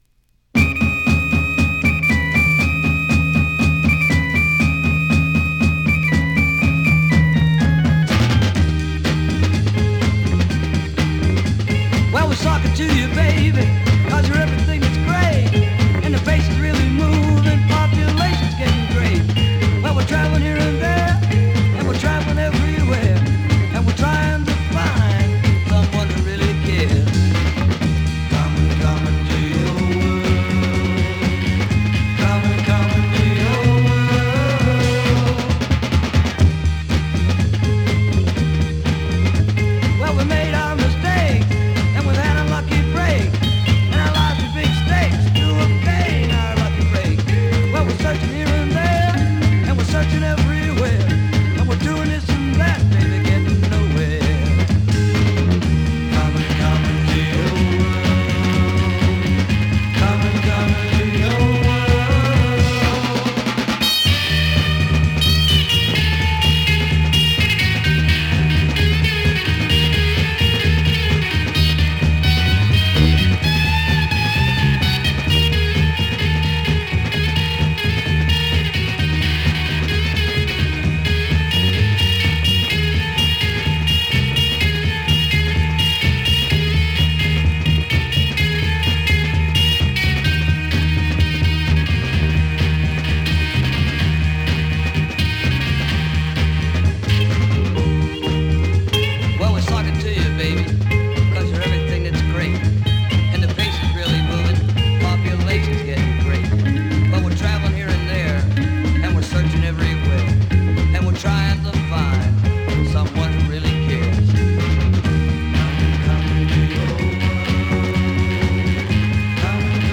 Rare psych fuzz garage double sider !!
試聴 (実際の出品物からの録音です)